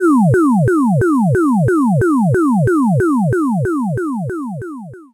2025 7/4 サーというノイズを取り除いて音をクリアにしました
場面転換・オープニング・エンディング